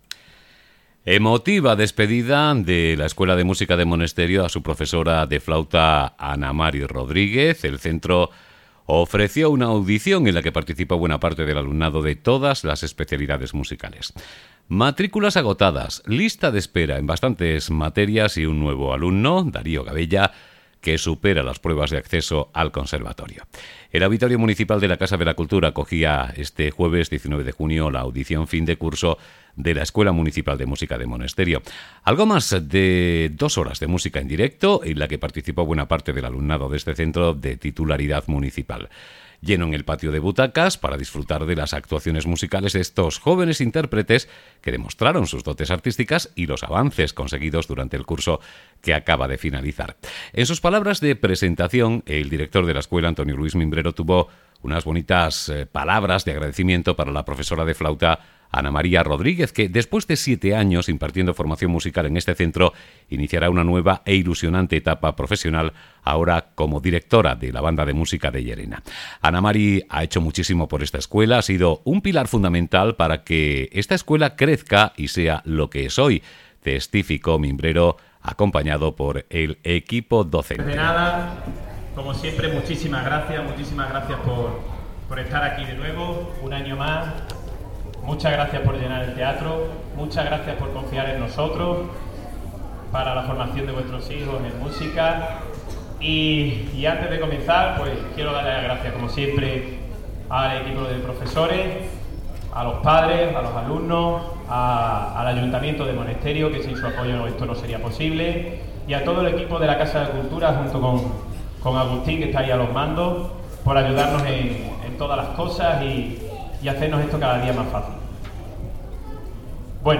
7mrv6CLAUSURACURSOESCUELAMSICA.mp3